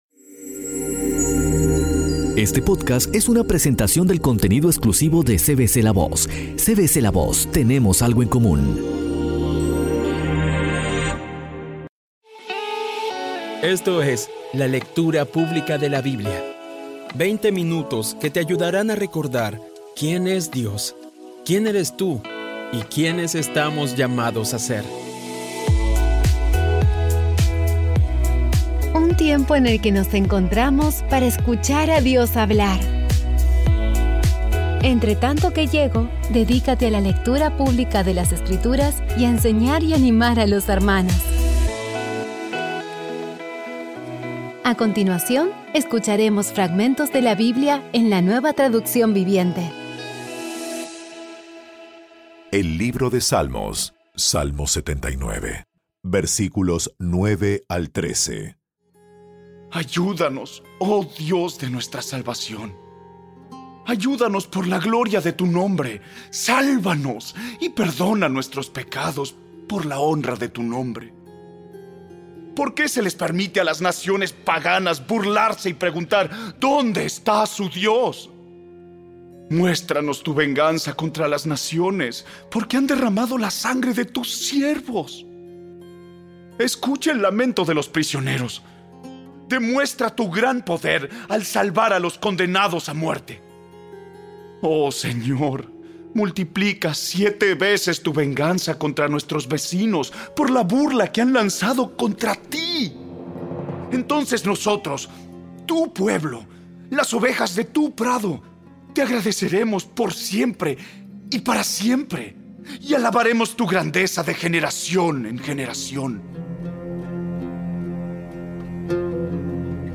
Audio Biblia Dramatizada Episodio 192
Poco a poco y con las maravillosas voces actuadas de los protagonistas vas degustando las palabras de esa guía que Dios nos dio.